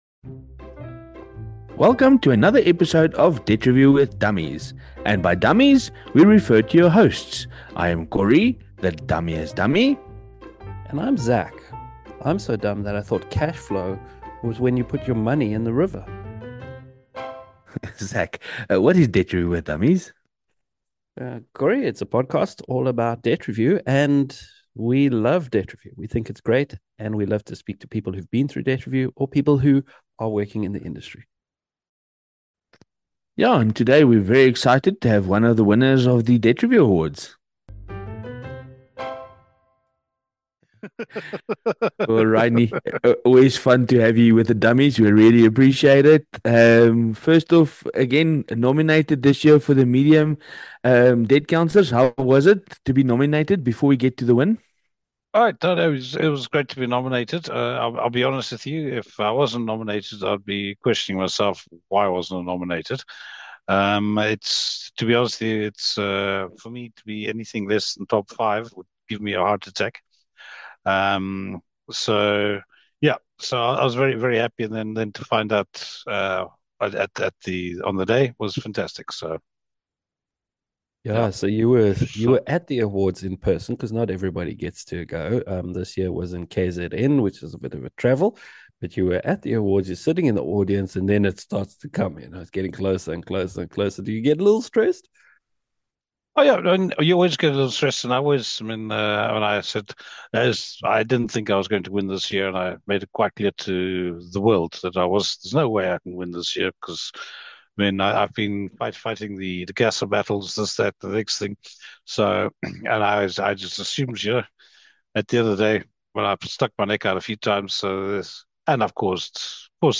Debt Review Awards Winner Chats